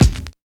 69 DIRT KIK2.wav